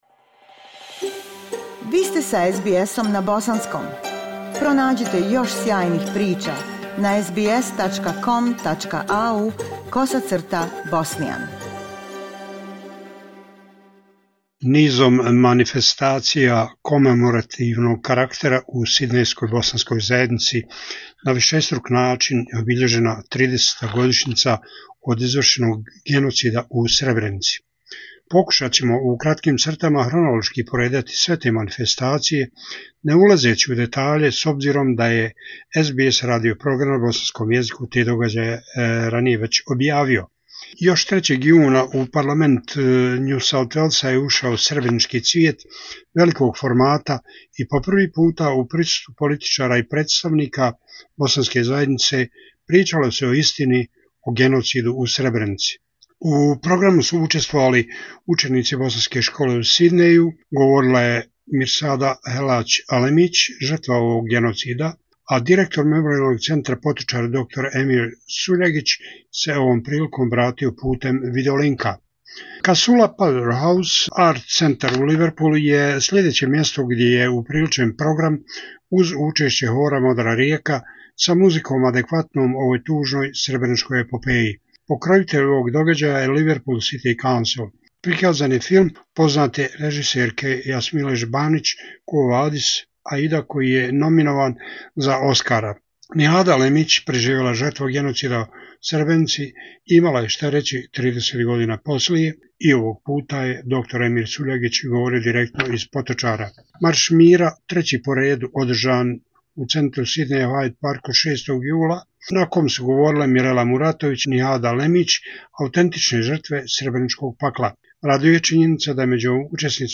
Izvještaj